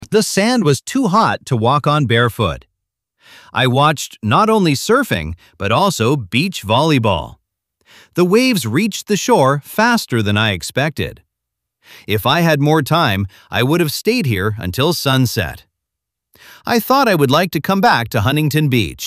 アメリカ人男性